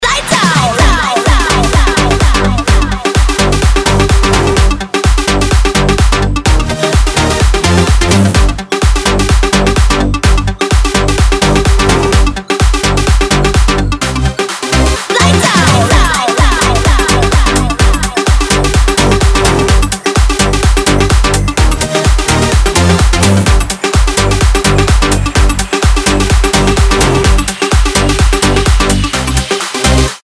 • Качество: 192, Stereo
Прикольный клубный мотивчик для мелодии на звонок